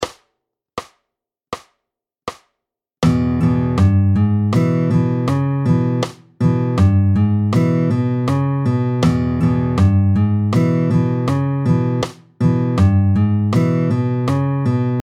32-13 Le honky tonk 3, petits barrés, tempo 80
Cette version développe, également sur 2 mesures, un contrechant dans les basses.